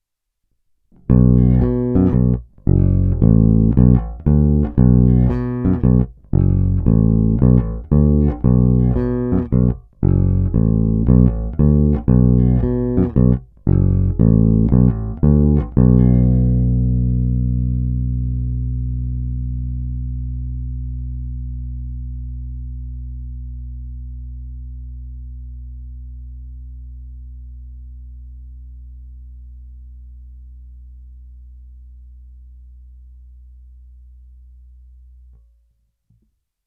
Zvuk je typický Precision.
Není-li uvedeno jinak, následující nahrávky jsou vyvedeny rovnou do zvukové karty, vždy s plně otevřenou tónovou clonou, a kromě normalizace ponechány bez úprav.
Hra nad snímačem